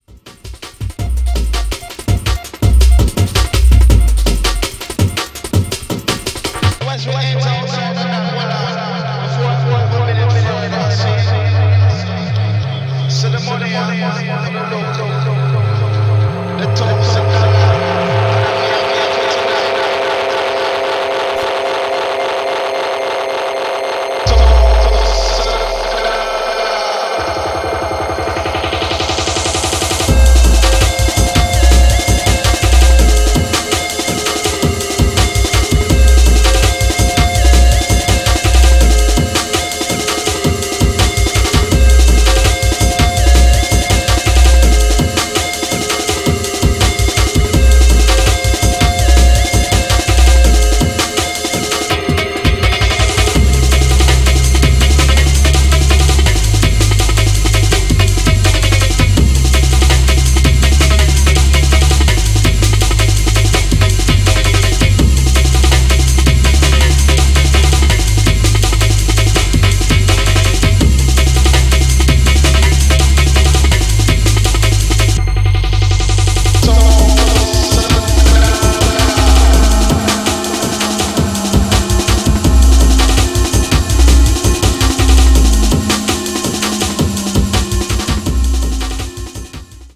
productions that move between 160-170 bpm
complex rhythms and ambient soundscapes
Drum and Bass Jungle